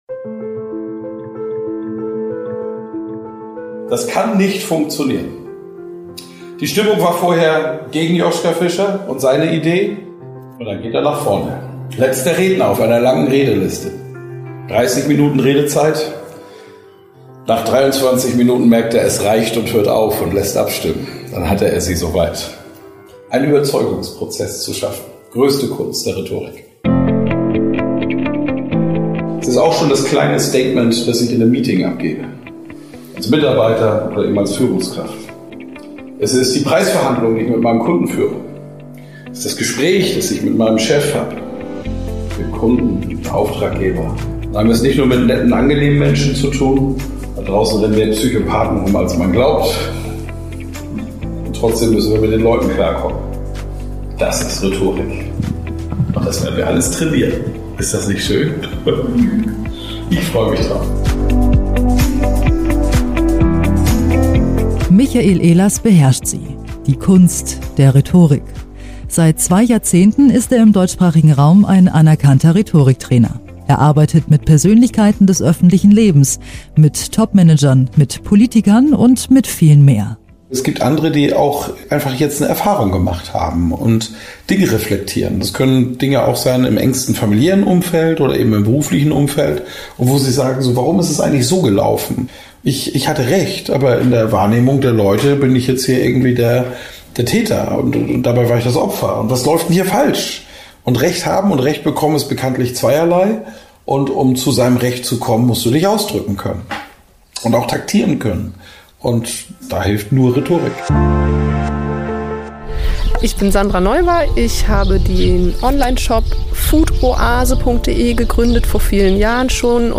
Ein Radio-Erlebnisbericht
Sie interviewte die Teilonehmenden und den Trainer.